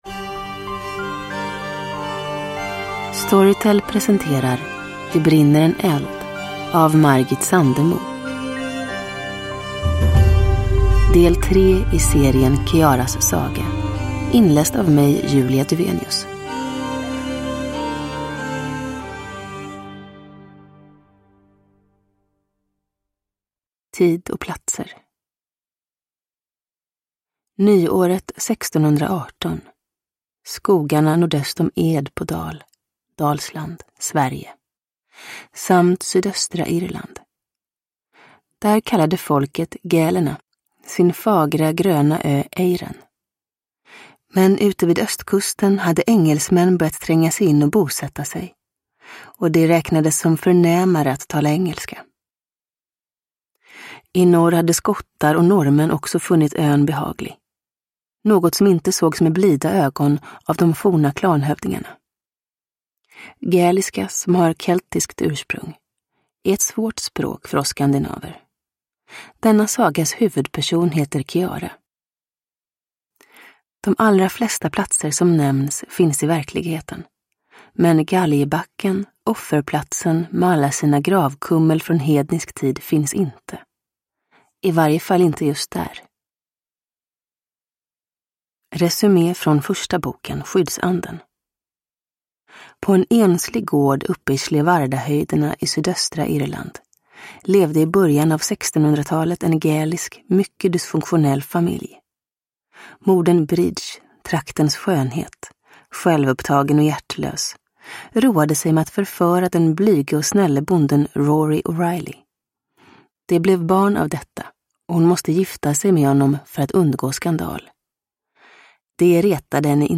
Det brinner en eld – Ljudbok – Laddas ner